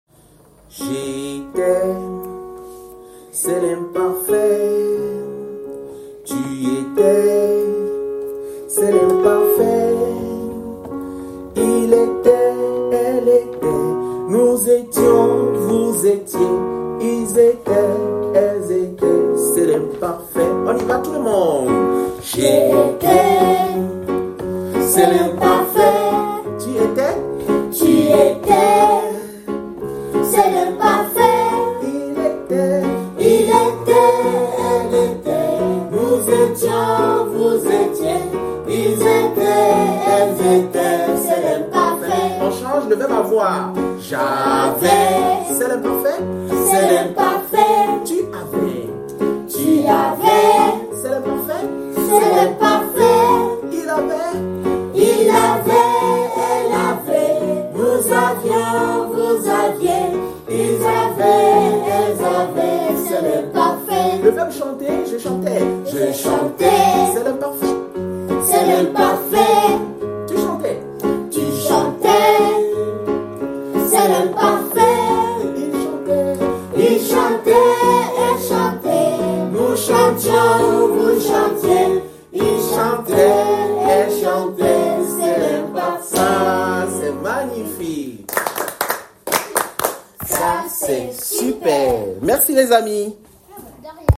Chanson : C’est l’imparfait